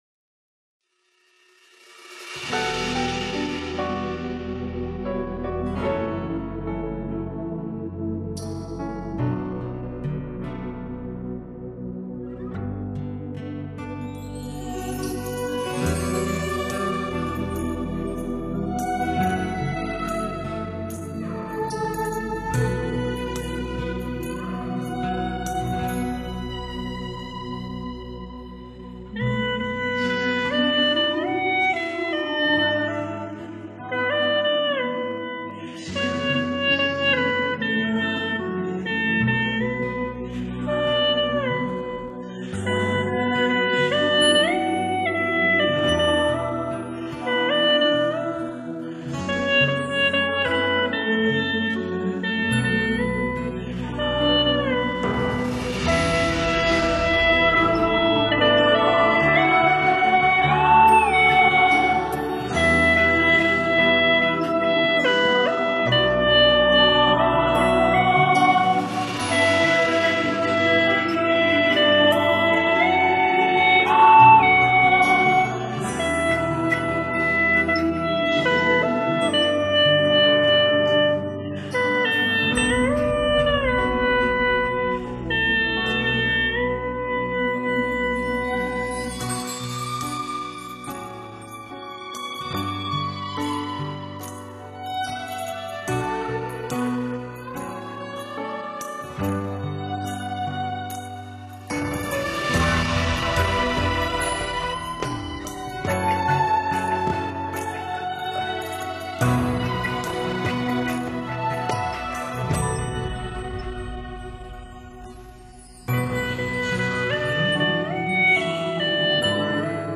但听葫芦丝演奏很好听哦。
吹的越来越有味了，滑音和腹颤在巩固，进步太大了，又是新曲子，找冷曲？